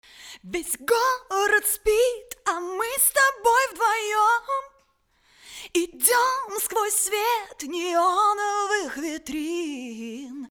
В сочетании с качественным аналого-цифровым преобразователем они позволяют добиться великолепного звучания!
Вы можете прослушать этот файл, подготовленный мною, и сделать выводы о качестве записи самостоятельно:
Надо лишь учесть, что формат mp3 немного ухудшает качество звучания.